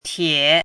“帖”读音
tiě